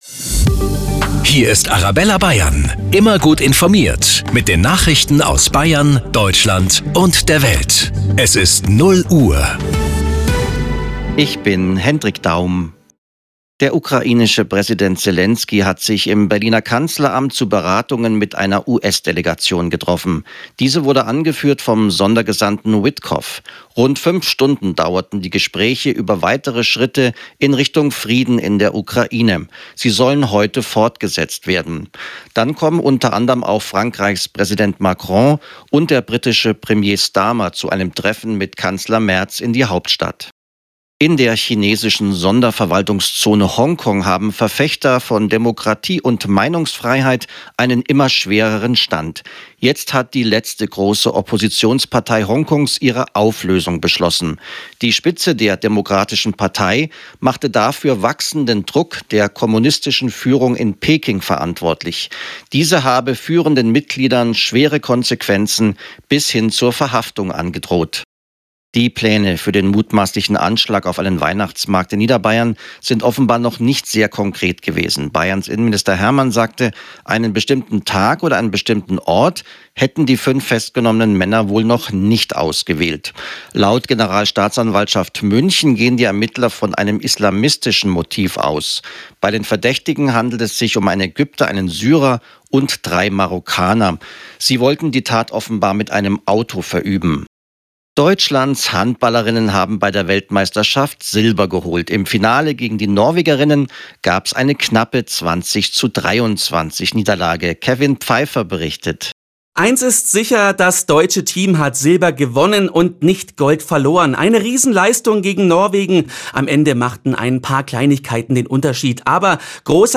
Die aktuellen Nachrichten zum Nachhören